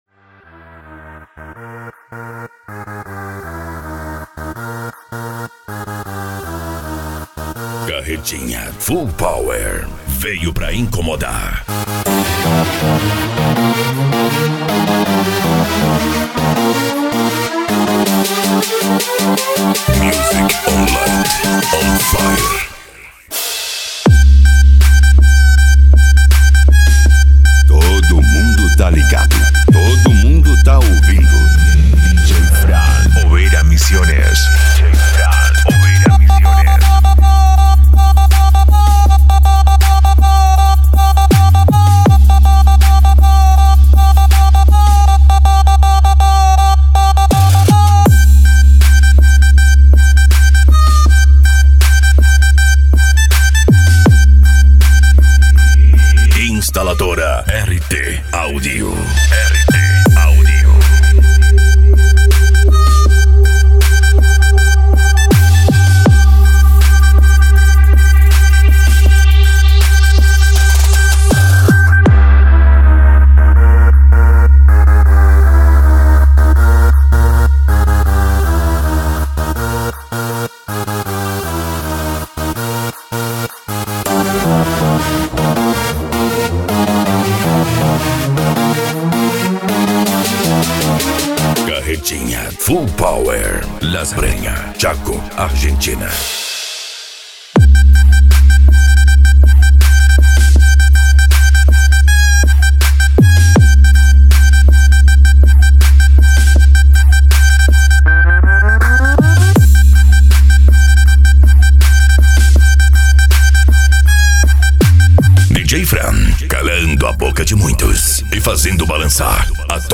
Bass
Remix
Musica Electronica